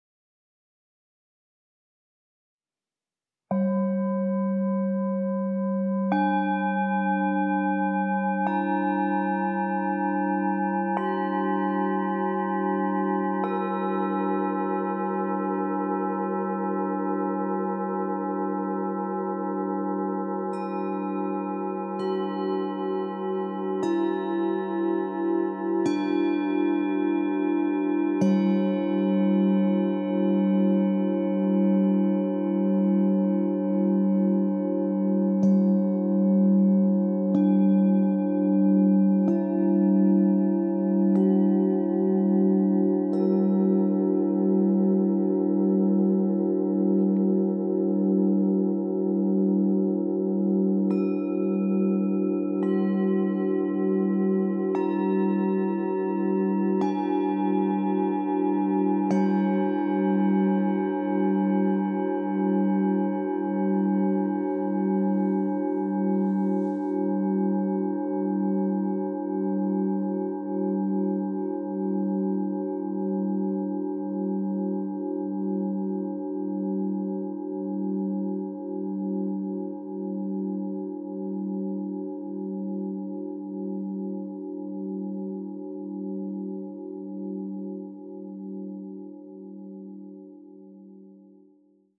Meinl Sonic Energy 5-piece Yoga Nidra Singing Bowl Set (SB-Y2)